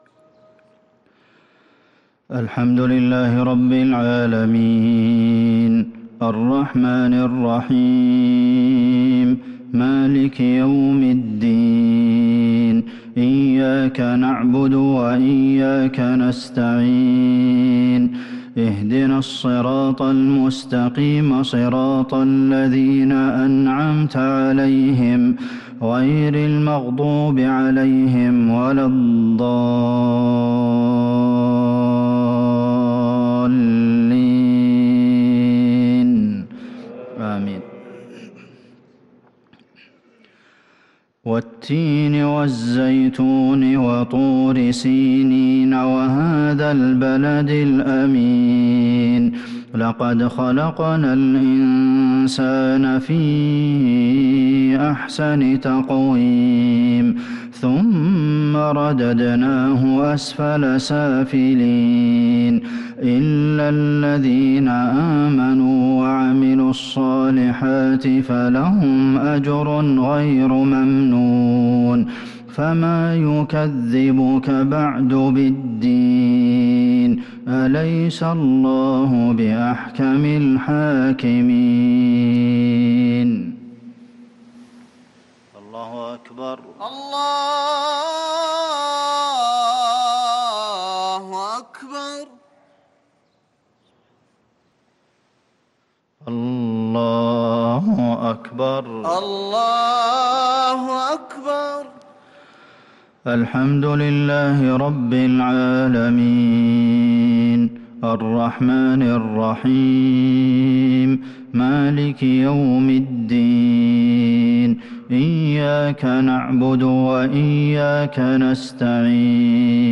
مغرب الثلاثاء 1-6-1443هـ سورتي التين و قريش | Maghrib prayer Surah At-Tin and Quraysh 4-1-2022 > 1443 🕌 > الفروض - تلاوات الحرمين